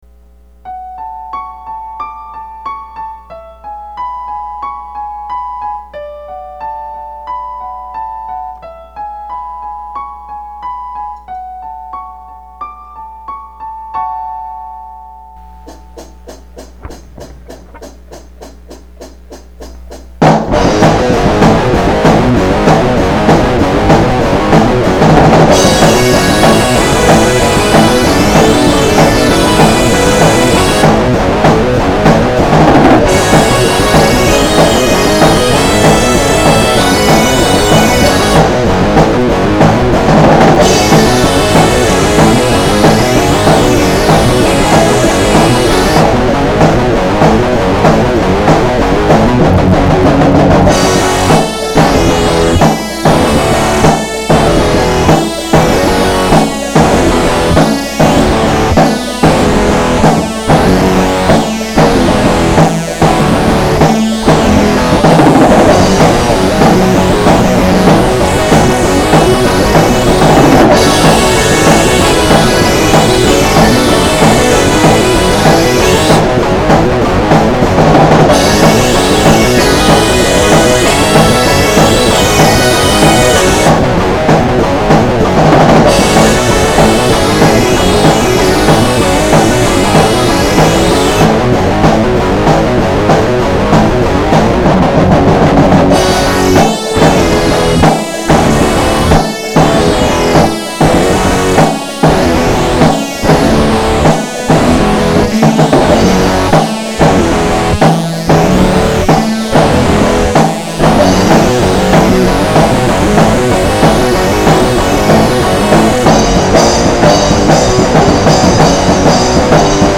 Metal Song
Instrumental completed; no lyrics/vocals